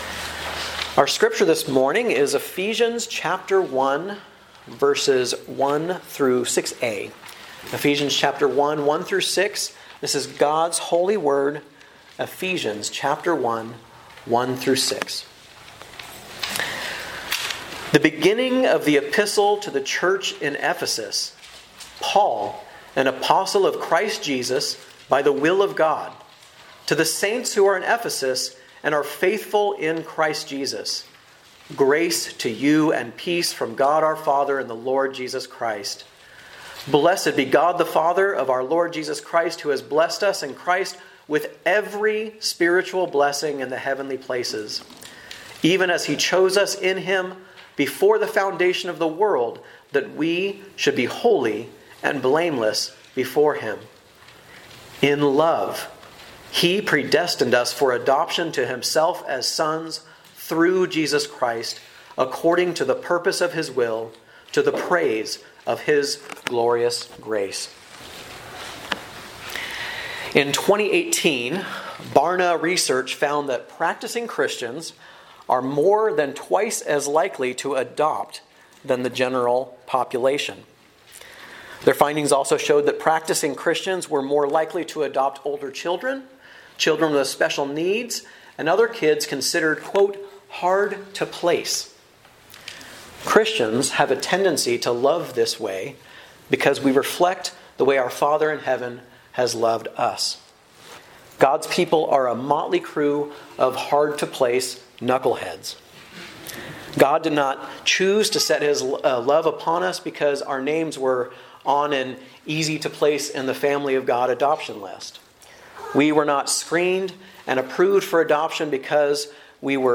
From Series: "Guest Preacher"